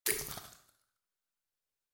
دانلود صدای آب 78 از ساعد نیوز با لینک مستقیم و کیفیت بالا
جلوه های صوتی